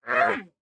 Minecraft Version Minecraft Version 1.21.4 Latest Release | Latest Snapshot 1.21.4 / assets / minecraft / sounds / mob / panda / cant_breed1.ogg Compare With Compare With Latest Release | Latest Snapshot
cant_breed1.ogg